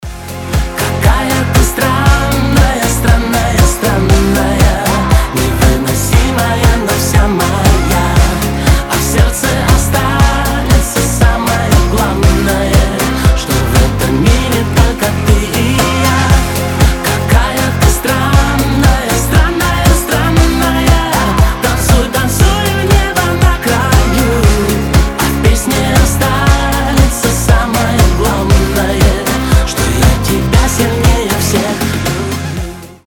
• Качество: 320, Stereo
эстрадные